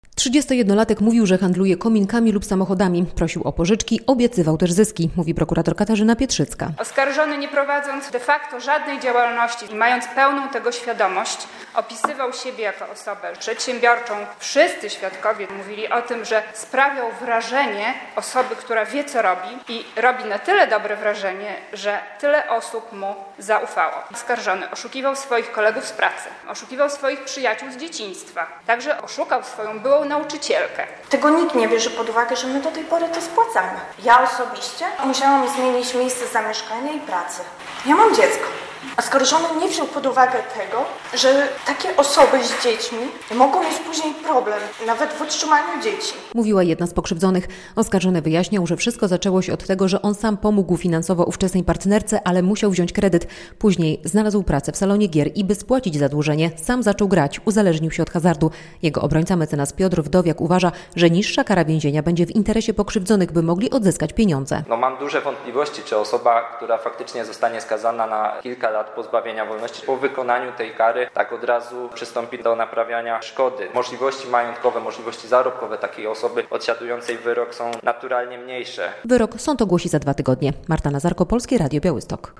Proces w sprawie oszustw na blisko 2 mln zł - relacja